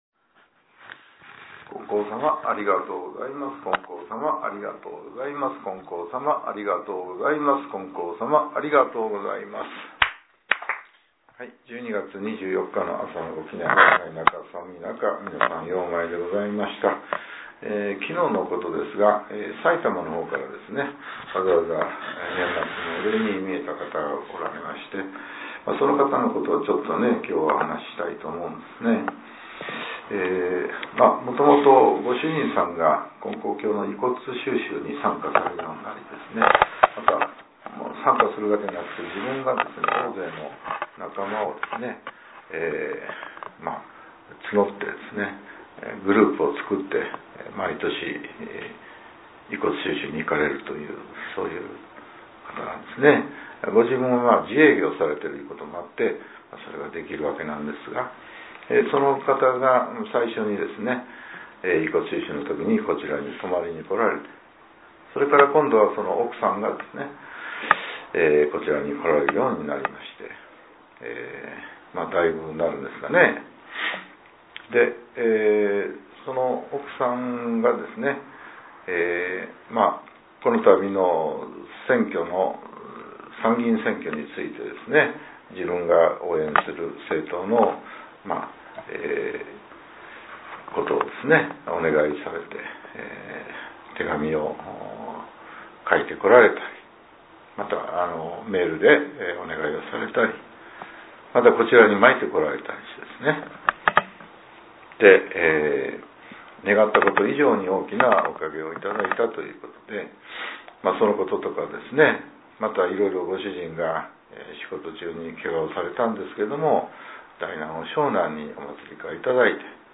令和７年１２月２４日（朝）のお話が、音声ブログとして更新させれています。